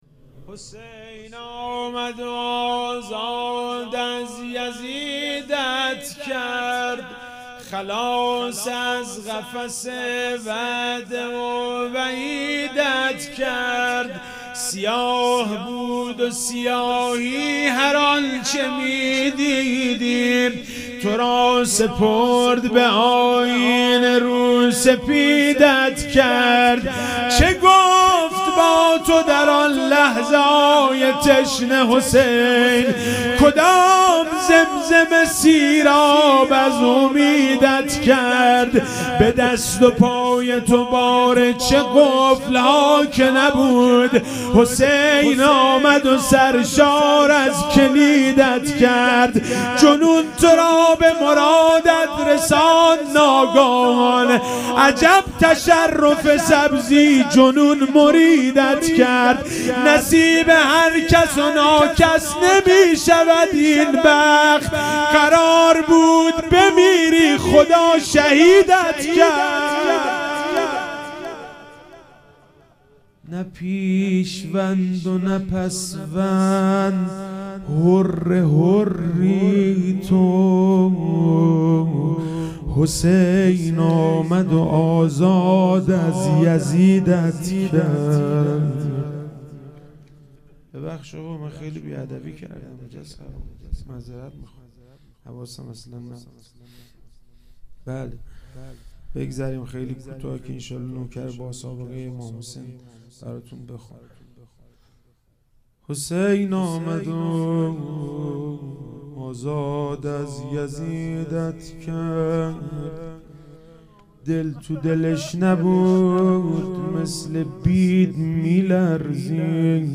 محرم99 - شب چهارم - روضه - حسین آمد و آزاد از یزیدت کرد